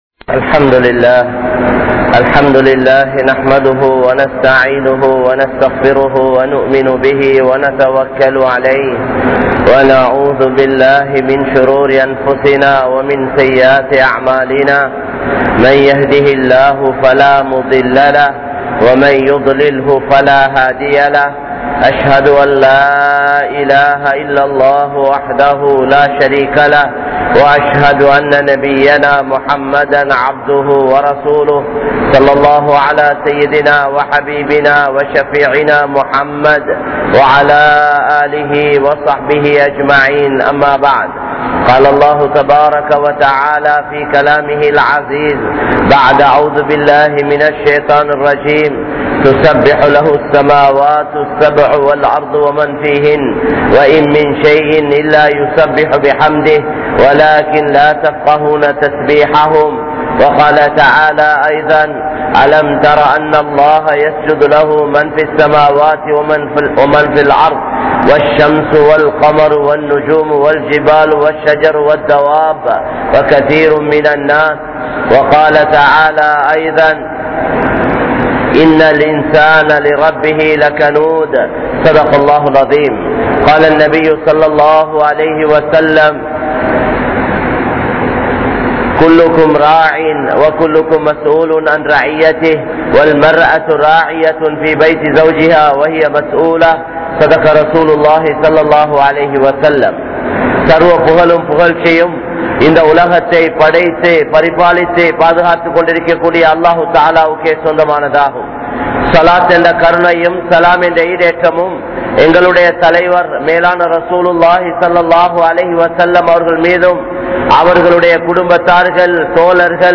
Masjidh Niruvaahihalin Kadamaihal (மஸ்ஜித் நிருவாகிகளின் கடமைகள்) | Audio Bayans | All Ceylon Muslim Youth Community | Addalaichenai
Wekanda Jumuah Masjidh